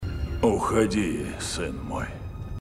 Звуки Дарт Вейдера